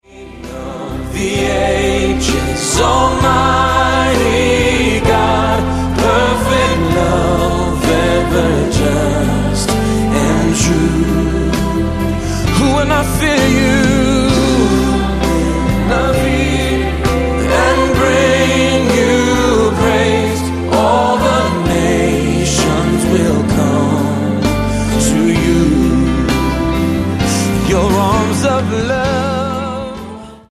• Sachgebiet: Praise & Worship